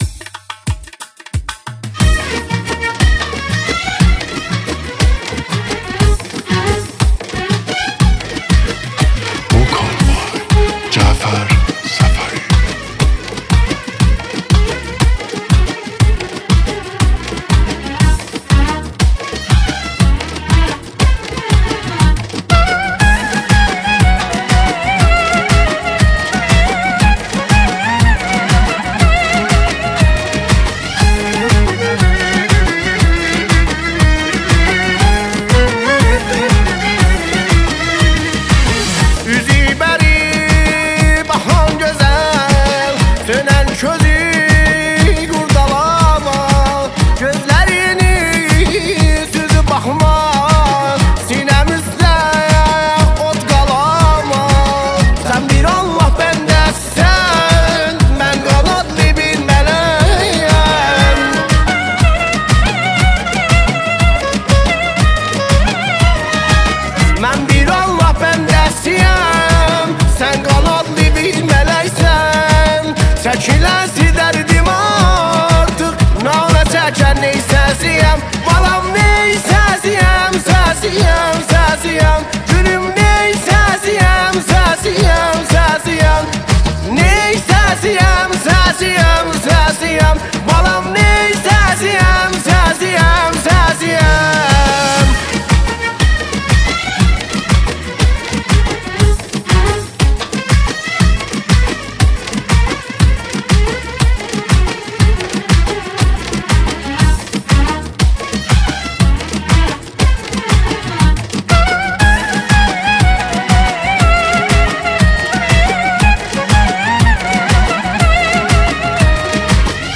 اهنگ ترکی